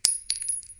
shellDrop3.wav